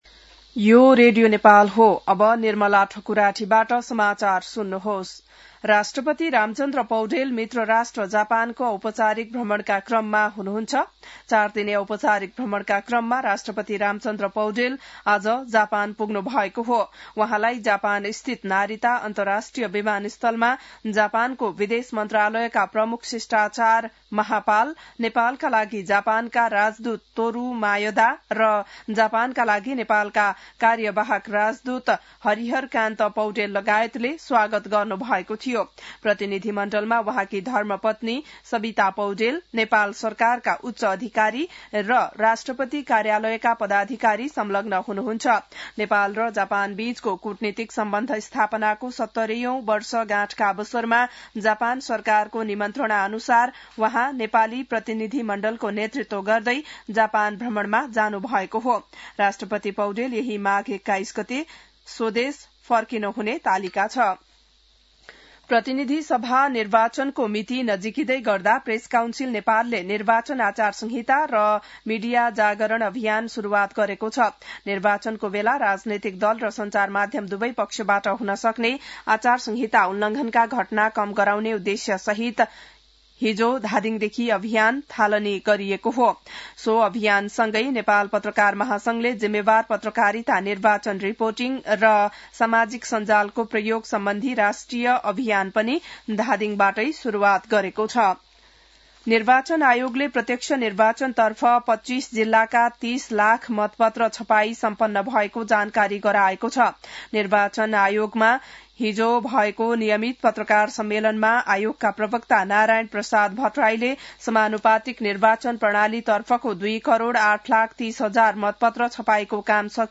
बिहान १० बजेको नेपाली समाचार : १९ माघ , २०८२